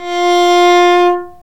Index of /90_sSampleCDs/Roland - String Master Series/STR_Viola Solo/STR_Vla3 Arco nv
STR VIOLA 0B.wav